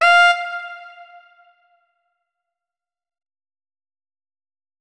saxophone
notes-53.ogg